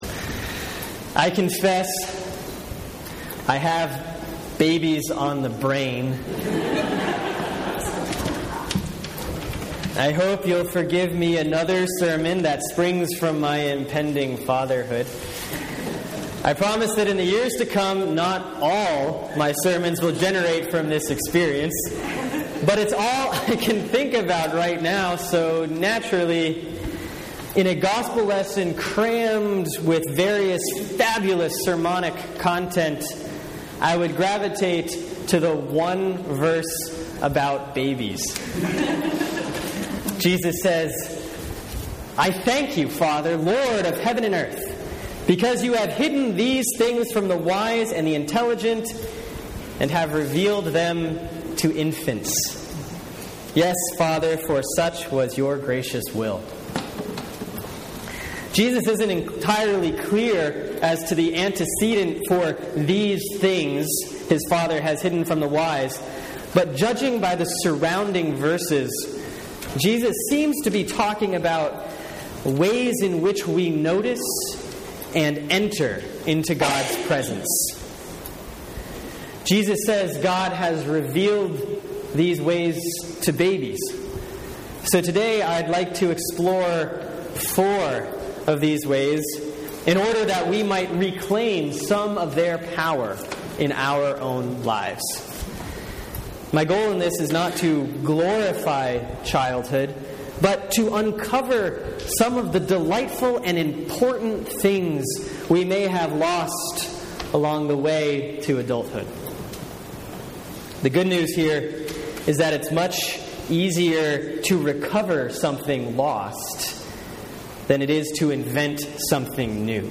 Sermon for Sunday, July 6, 2014 || Proper 9A || Matthew 11:16-19, 25-30